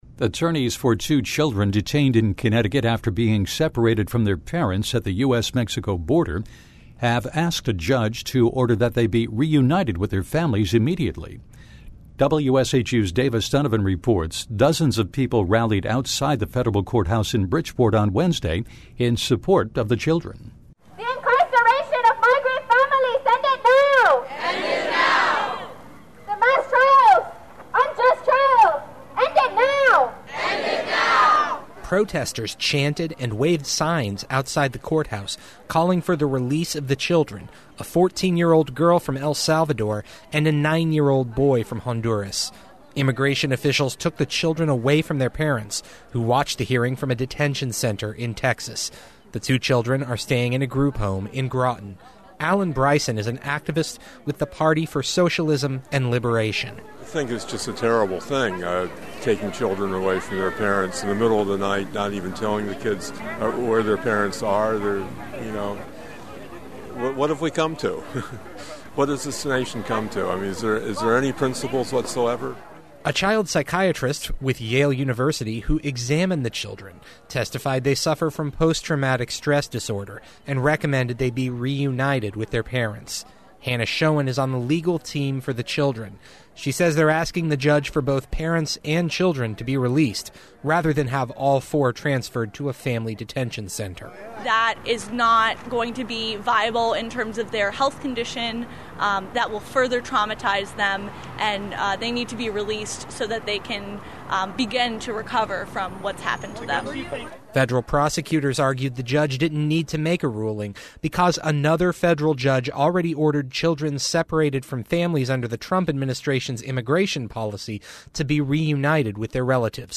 Attorneys for two children detained in Connecticut after being separated from their parents at the U.S.-Mexico border have asked a judge to order that they be reunited with their families immediately. Dozens of people rallied outside a district courthouse in Bridgeport today in support of the children.
Protesters chanted and waved signs outside the courthouse calling for the release of the children, a 14-year-old girl from El Salvador and a 9-year-old boy from Honduras.